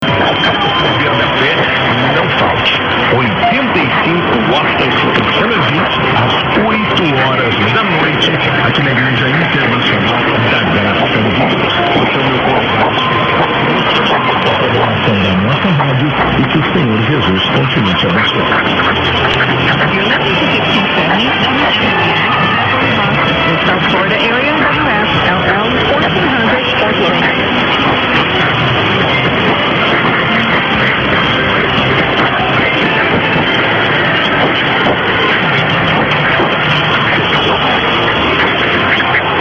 They obviously were on day power on 4th Sept when I caught the following id, which had me trying to match up a // Florida station to 1400khz!!!